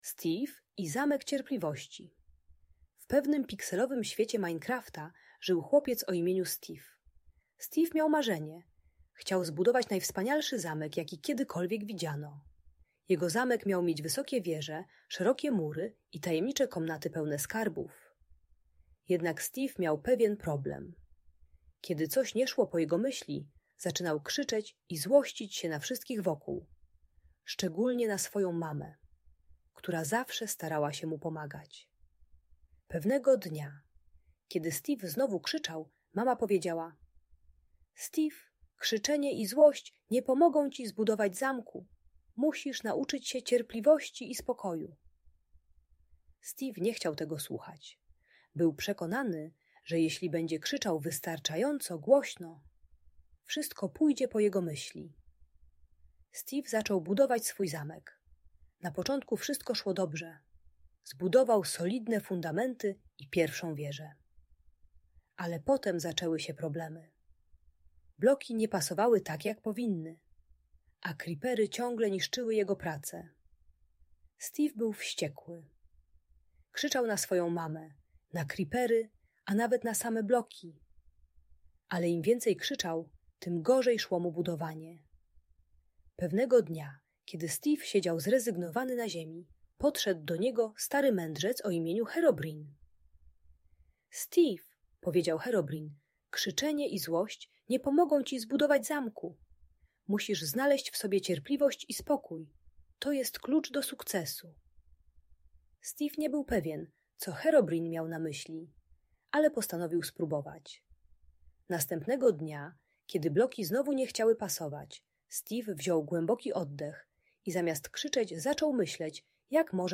Steve i Zamek Cierpliwości - Agresja do rodziców | Audiobajka